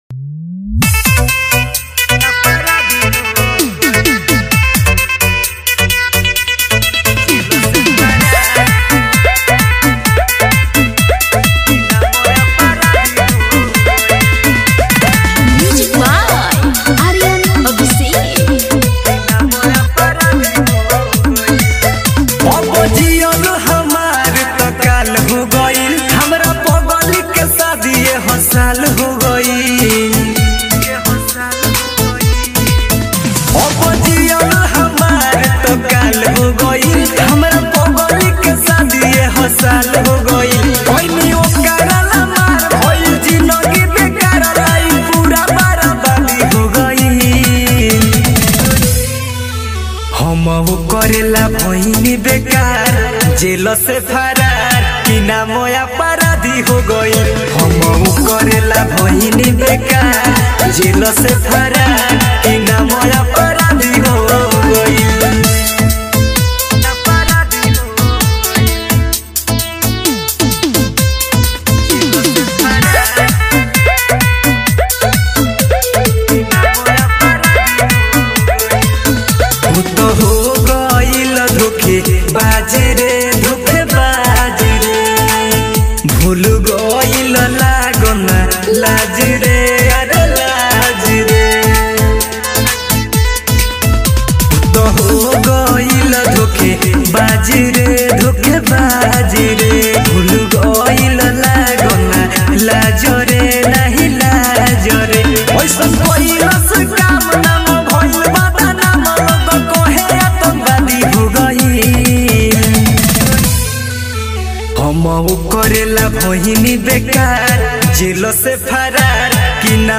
sadsong